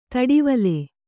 ♪ taḍivale